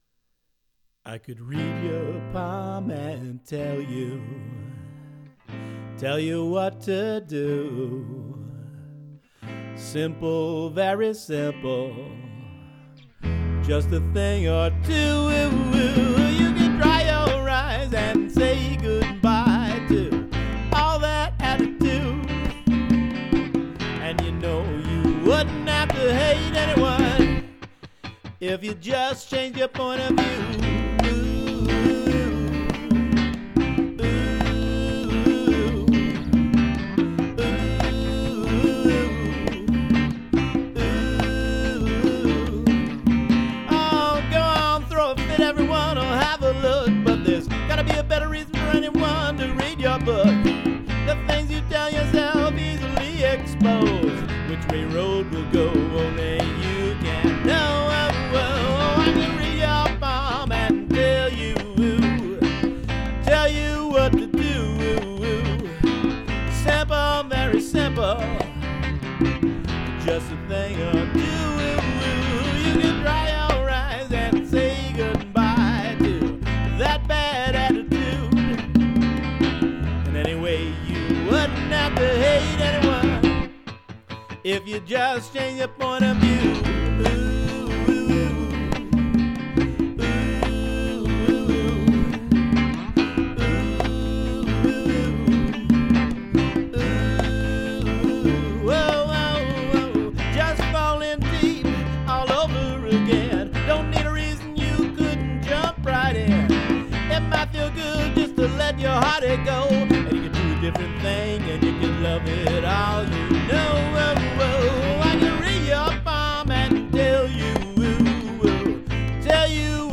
on congas and harmony.
Acoustic Soul with a Latin Groove